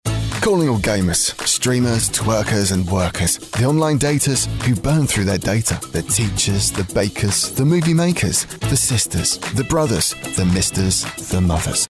His accent can be is neutral if required or a very unique Northern Yorkshire accent. His age range is late 30s to early 50s and has voiced a wide variety of projects ranging from corporate videos through to commercials.